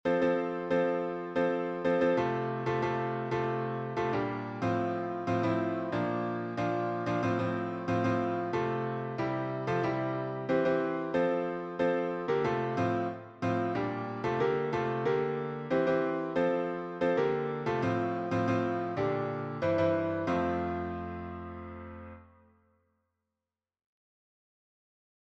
simple, upbeat song
A cappella
SATB